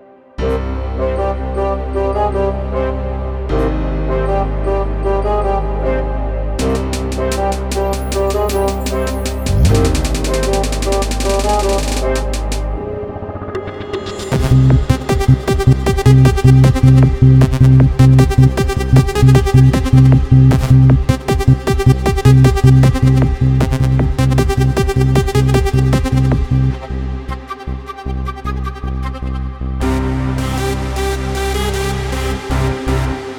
המקצבים שלי